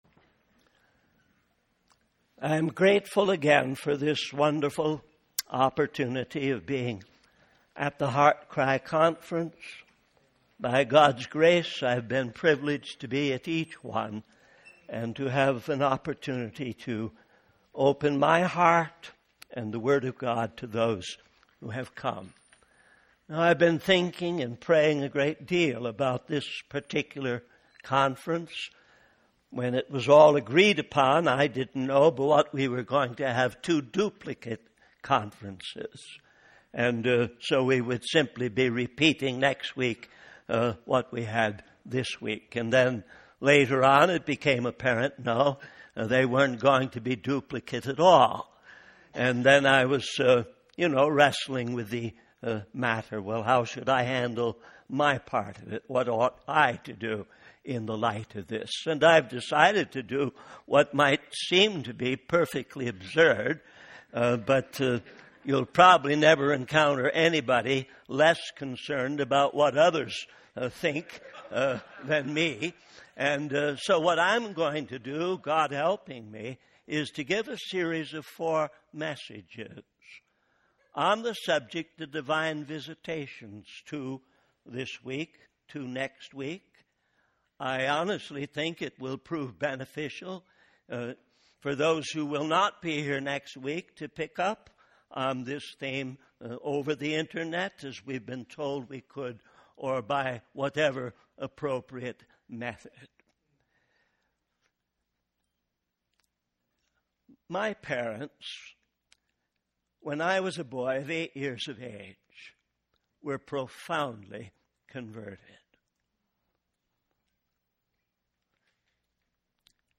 In this sermon, the speaker discusses the concept of divine visitations.